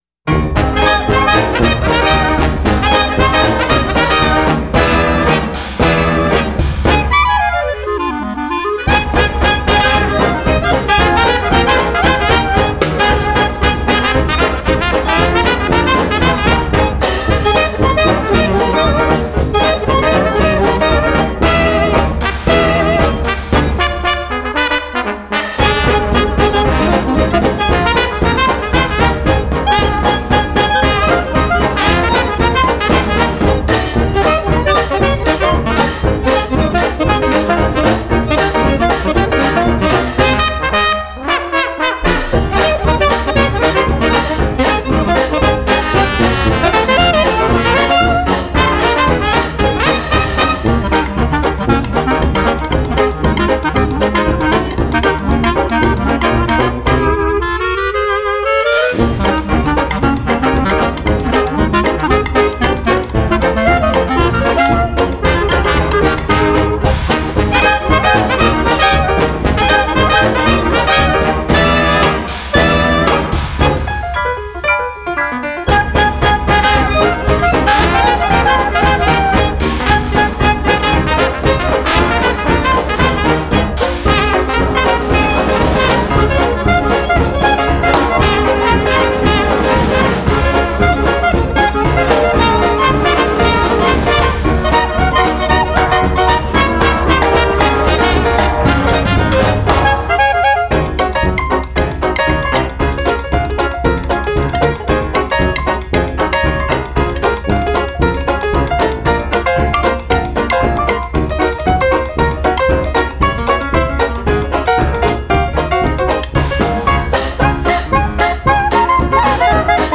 this jazz arrangement
The piece is very playful; I love its beat.
Genre: Ragtime